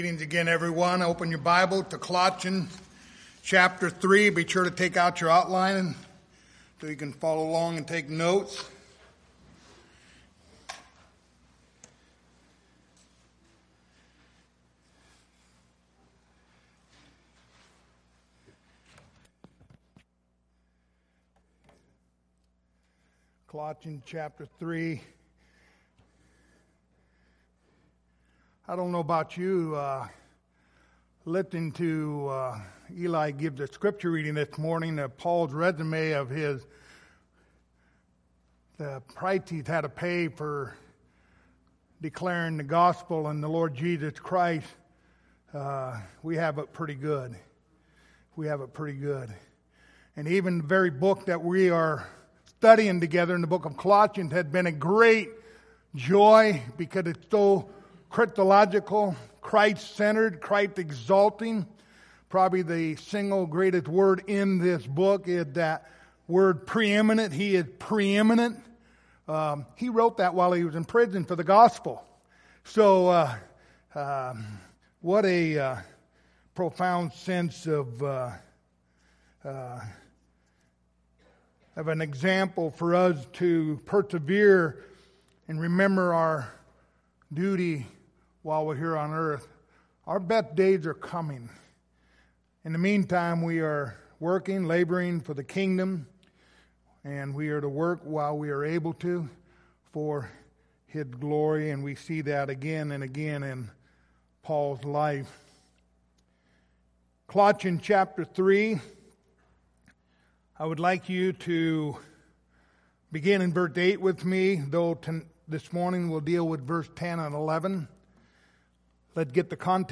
Passage: Colossians 3:10-11 Service Type: Sunday Morning